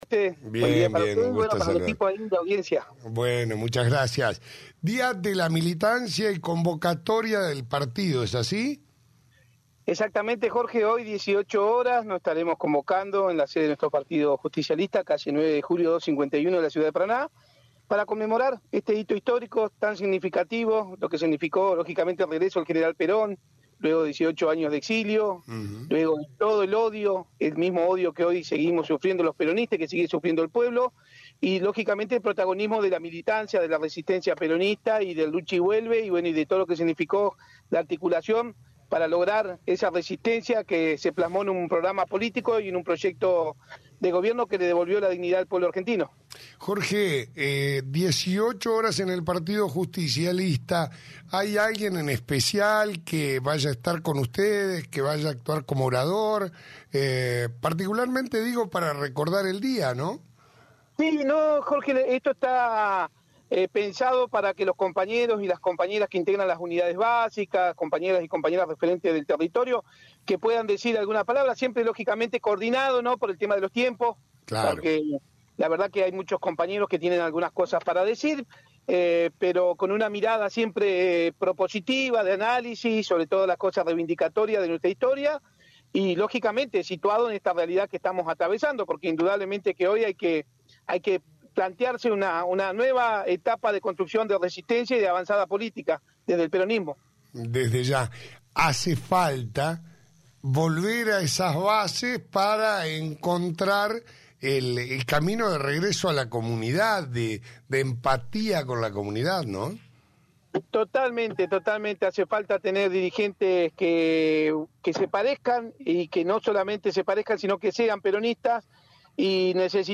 AUDIO. Entrevista